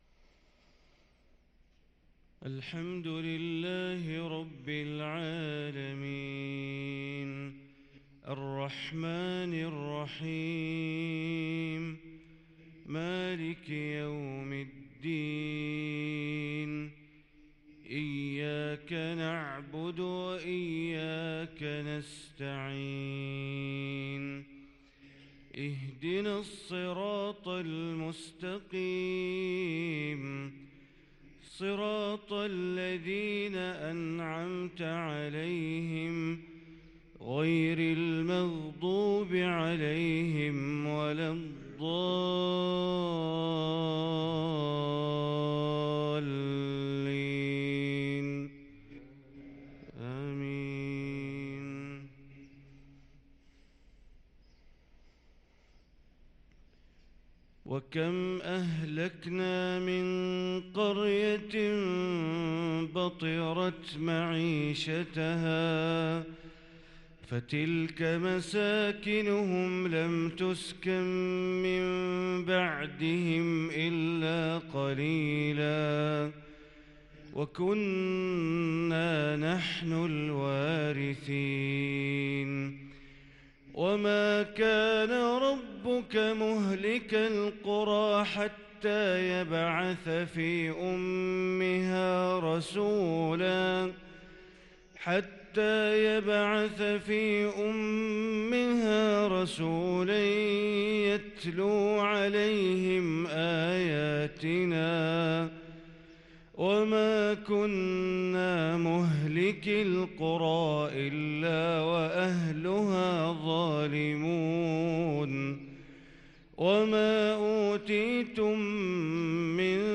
صلاة الفجر للقارئ بندر بليلة 25 ربيع الآخر 1444 هـ